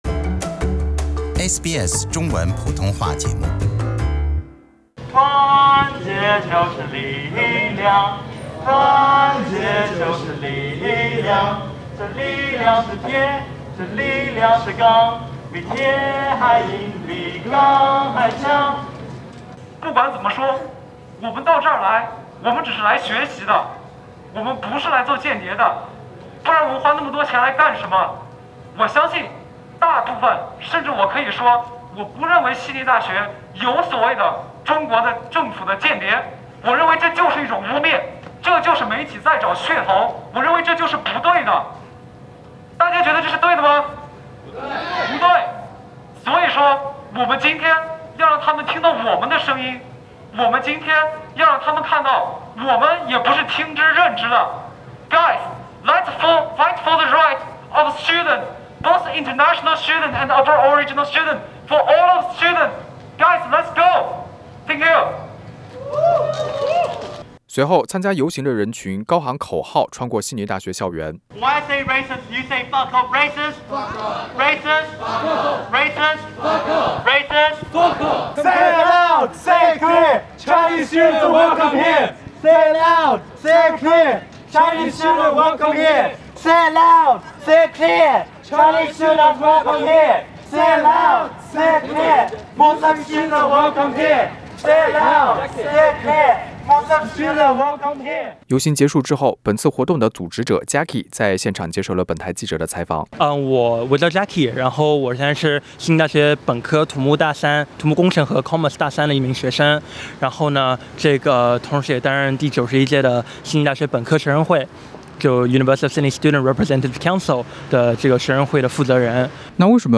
点击收听现场采访： LISTEN TO 悉大学生“反排华、反歧视”游行：我们不是间谍！